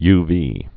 (yvē)